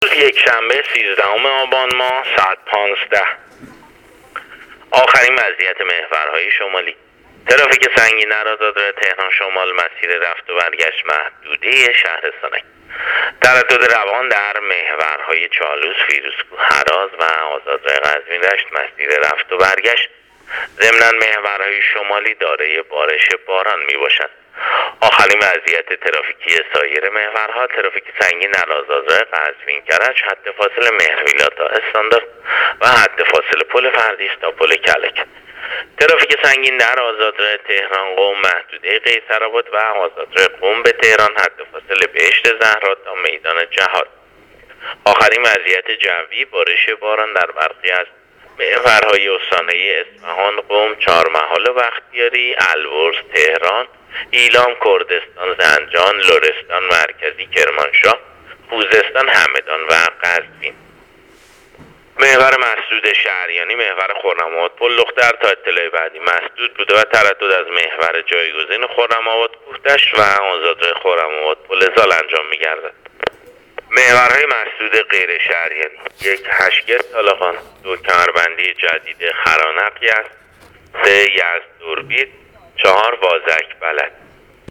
گزارش رادیو اینترنتی از آخرین وضعیت ترافیکی جاده‌ها تا ساعت ۱۵ روز ۱۳ آبان؛